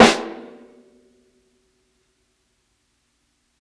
60s_SNARE_RIMSHOT.wav